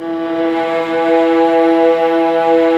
Index of /90_sSampleCDs/Roland LCDP13 String Sections/STR_Violas II/STR_Vas4 Amb p